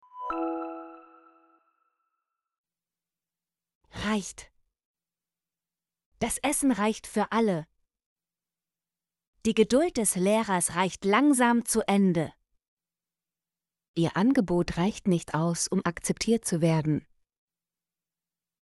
reicht - Example Sentences & Pronunciation, German Frequency List